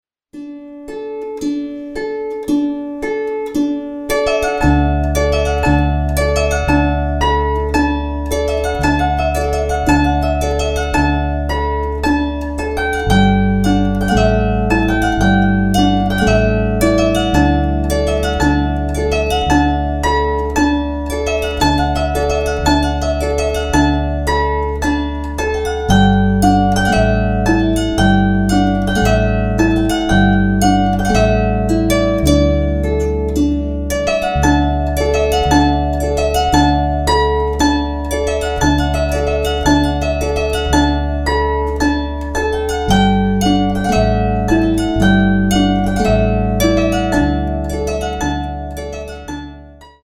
Die Kombi aus Harfe, Gitarre, Blockfl�te,